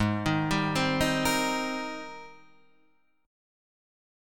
G# Major 9th